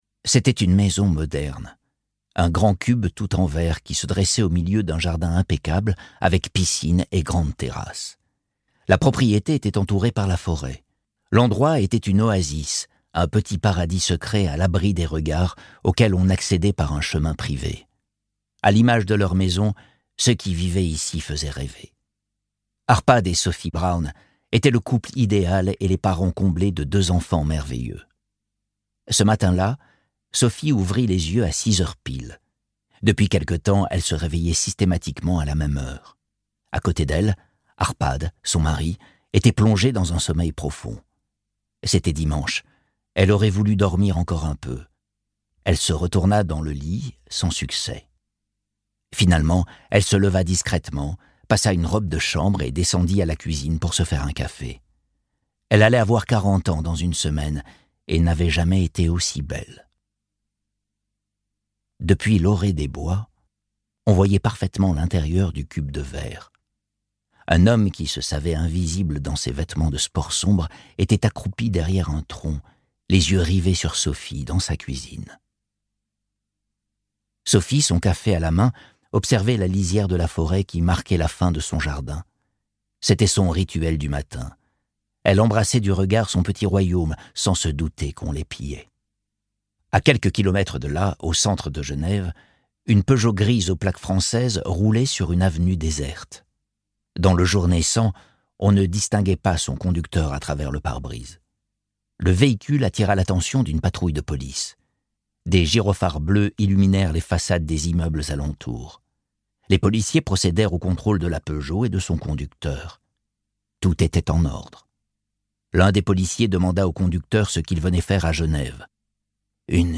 Mais quand l'image de papier glacé se froisse, son timbre légèrement granuleux et sa lecture aux accents canaille sont tout aussi efficaces pour raconter la chute et les nombreux secrets qui les dévorent.